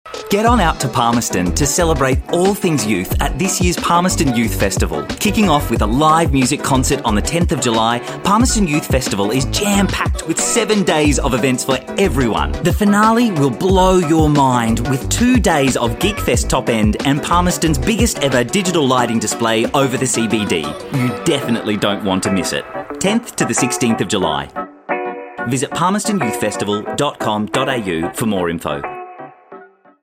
Australian male voiceover artist and actor ready to give
Australian, British
Commercial
A confident, energised and relatable voice with plenty of range.